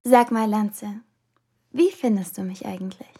1½ Ritter – Auf der Suche nach der hinreißenden Herzelinde Sample 1 Datei herunterladen weitere Infos zum Spiel in unserer Spieleliste Beschreibung: Erstes Sprachbeispiel zur Rolle der Herzelinde.